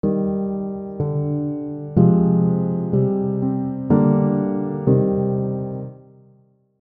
Chord suspension
The example above features two moments of brief tension by simply extending one note of a triad while moving one and two notes, respectively.